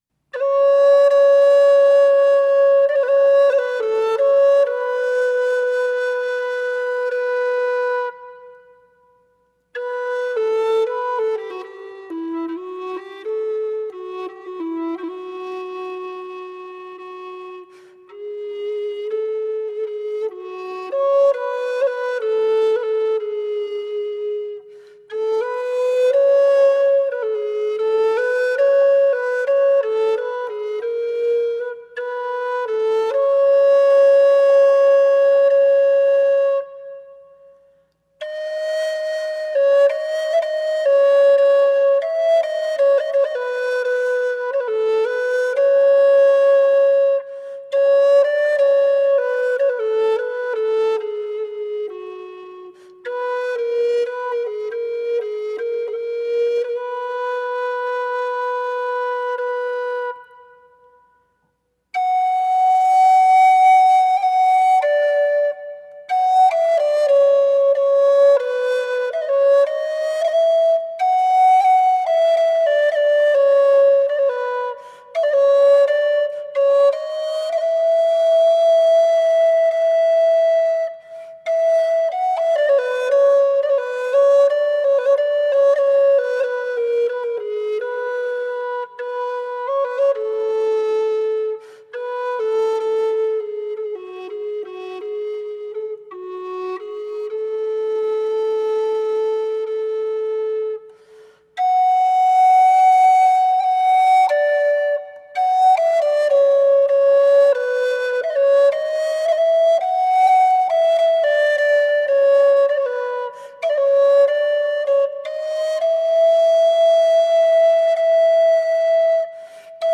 Medieval music from the 12th to the 15th centuries.
medieval instrumental music